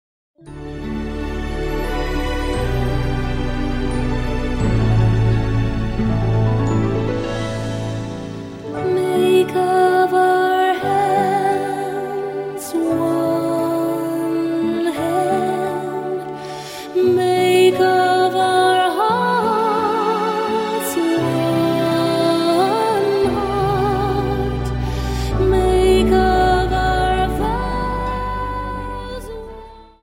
Dance: Slow Waltz Song